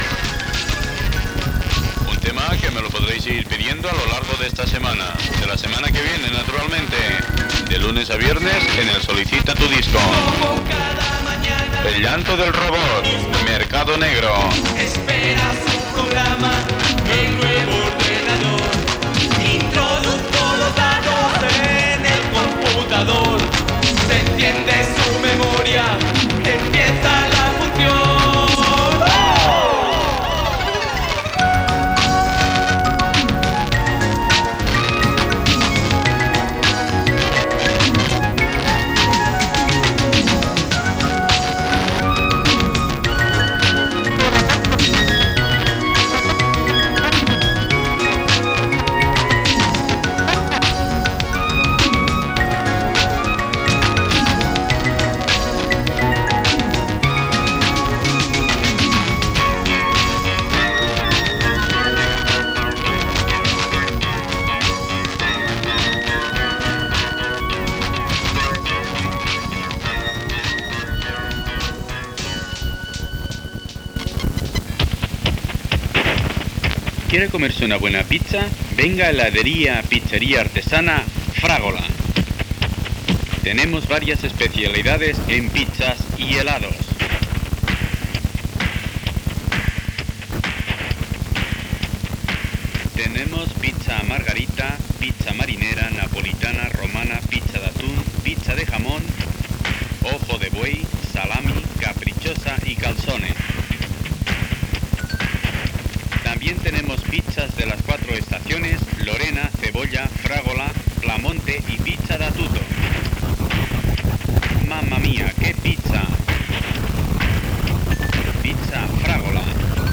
Tema musical i publicitat.
FM